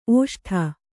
♪ ōṣṭha